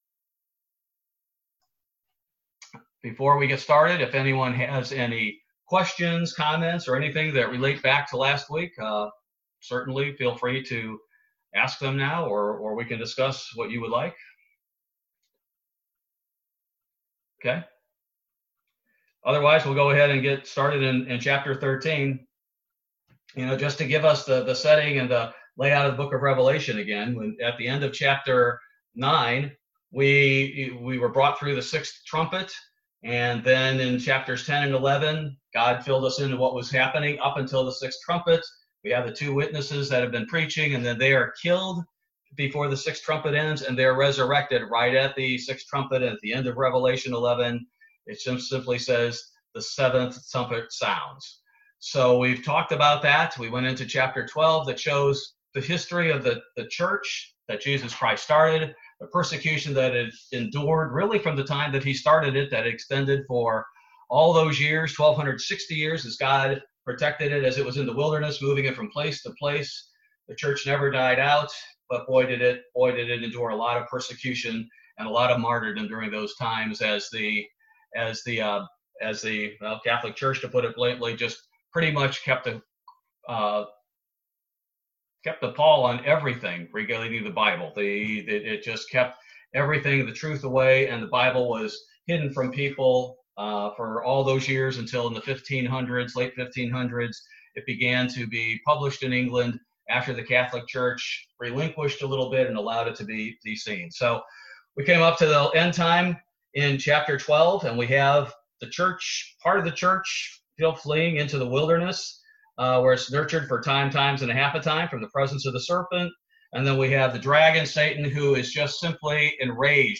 Bible Study - September 16, 2020